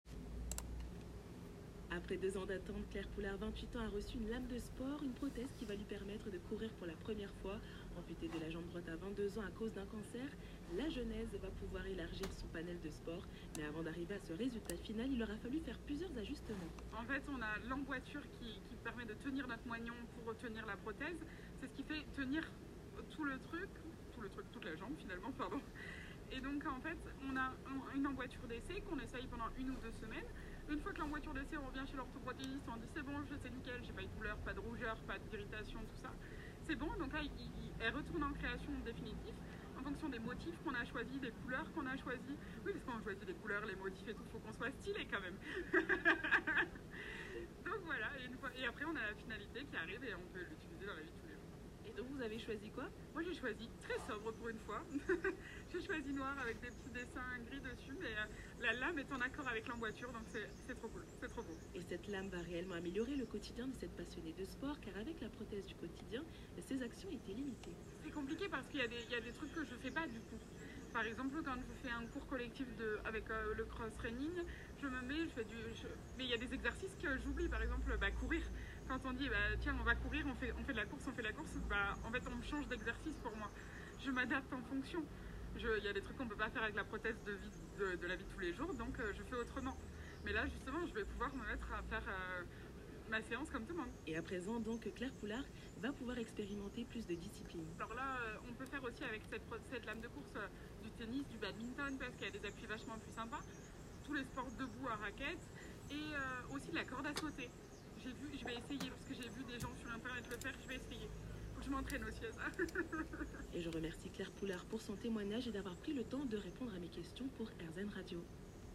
Air Zen Radio Interviews